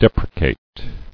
[dep·re·cate]